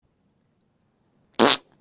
Goofy Ahhh Fart Sound Button - Free Download & Play
Fart Soundboard997 views